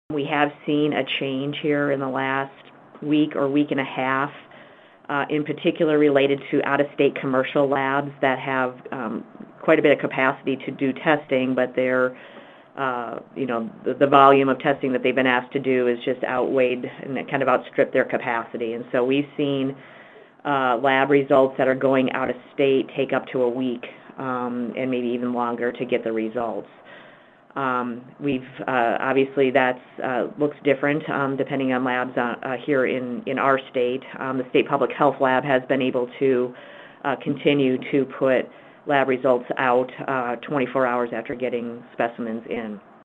South Dakota Secretary of Health Kim Malsam-Rysdon says how quickly results come back depends on which lab is doing the testing.